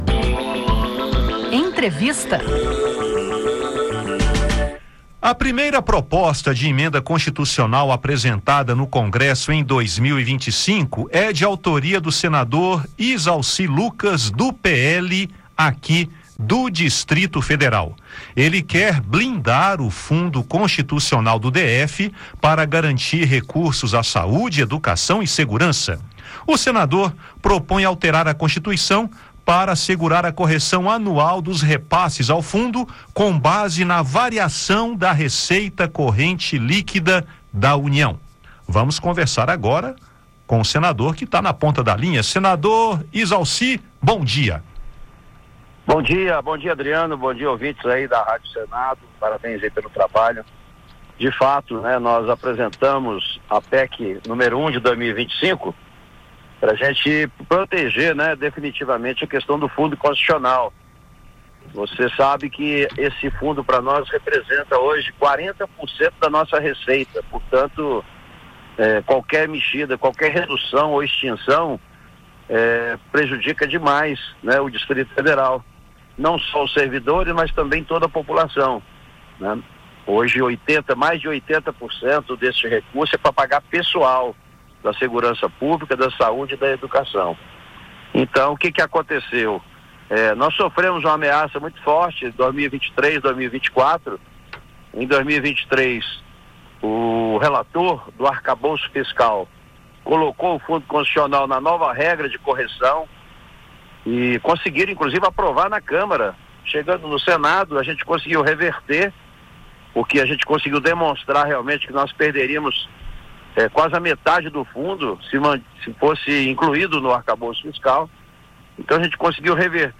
A PEC 1/2025 determina que o Fundo Constitucional do Distrito Federal (FCDF) seja corrigido anualmente variação da receita corrente líquida (RCL) da União. Em entrevista ao programa Conexão Senado, da Rádio Senado, Izalci diz ser preciso garantir a correção anual dos repasses para a educação, a saúde e a segurança do Distrito Federal.